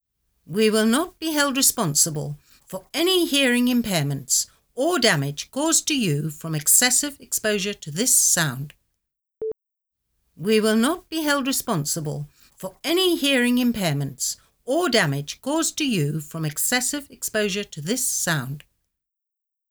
Below I leave an mp3 without applying the effect and after applying. It is a radio ad that communicates the sale of a car. It is normal that they do not understand what I say because I speak Spanish from Argentina.